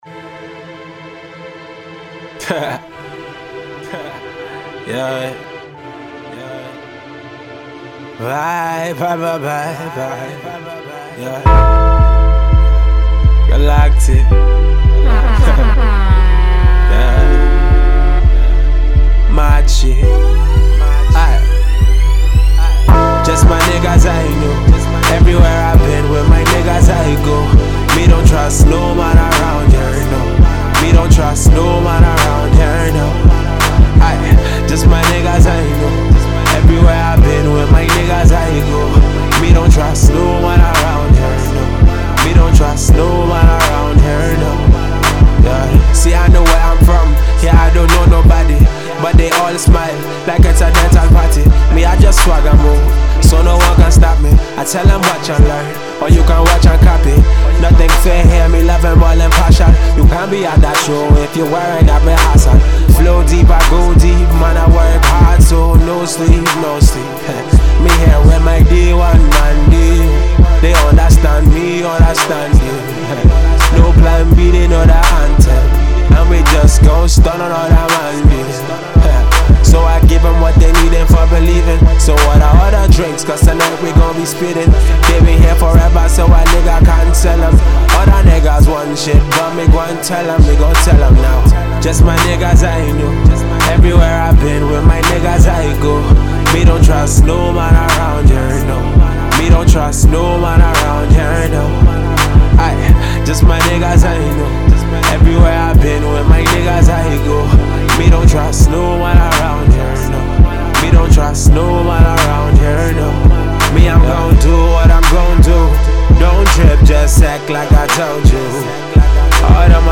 freestyle tune